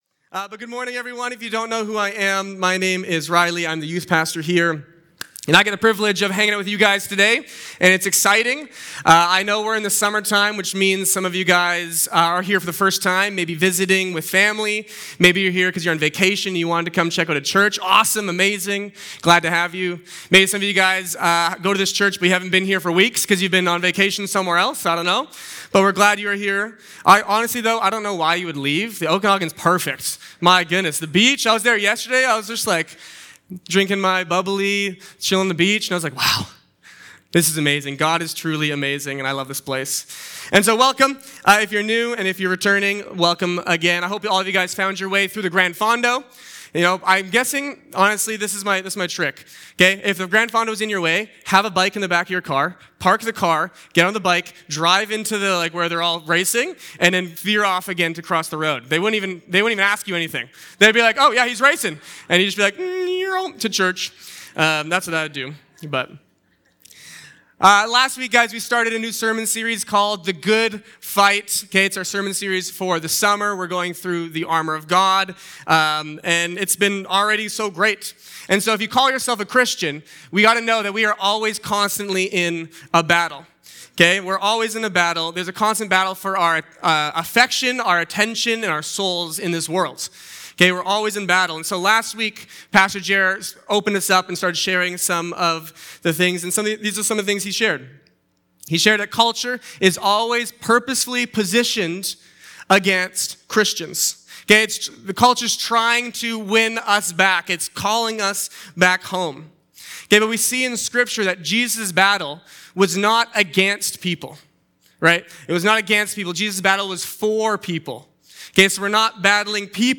Sermons | Bethel Church Penticton